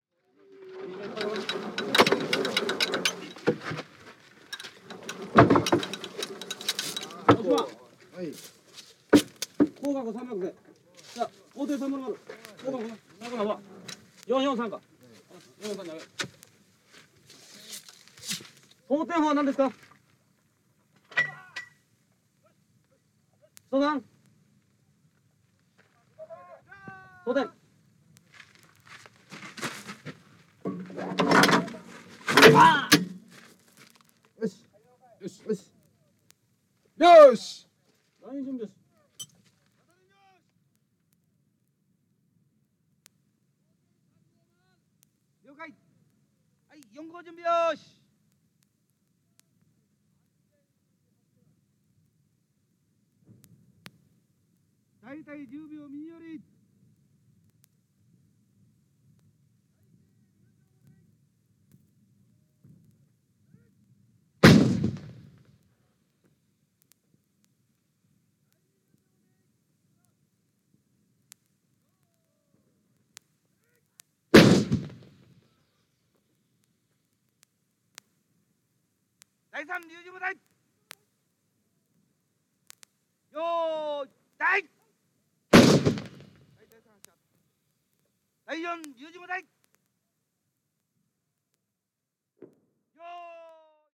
日本の自衛隊・演習のレコードです。